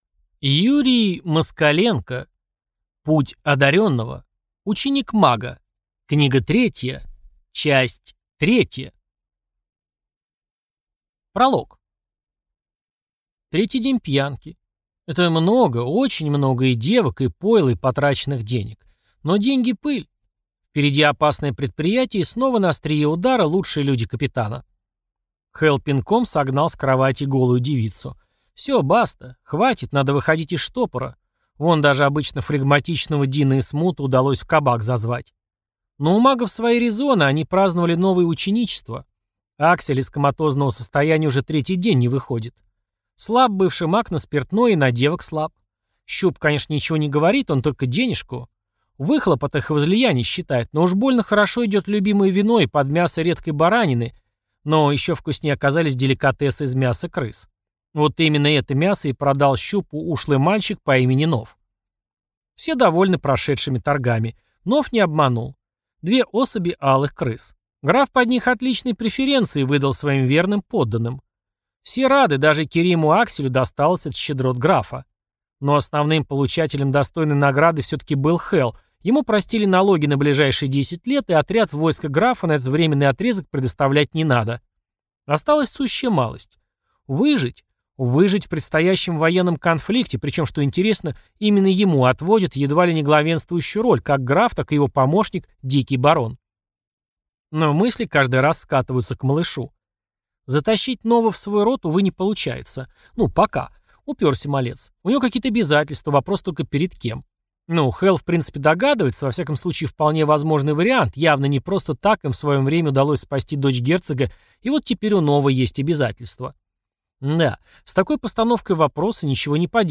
Аудиокнига Путь одарённого. Ученик мага. Книга третья. Часть третья | Библиотека аудиокниг